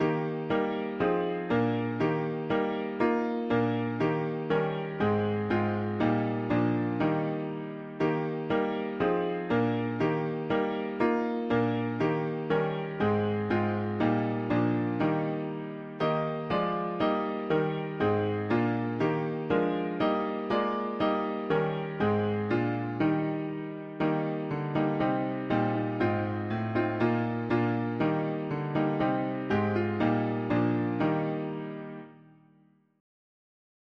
Key: E-flat major